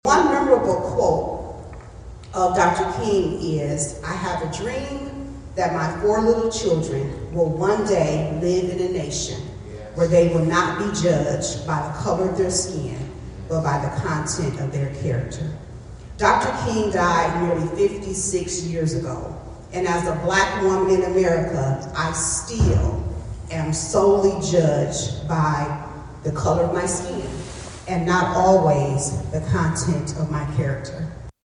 To “remain committed to the change” was the theme for Sunday night’s rescheduled Martin Luther King Jr Celebration, held at First United Methodist Church and organized by the Eastside Community Group.
Speakers at the event reminded the audience that change has happened, but more change is needed to make society equal for all.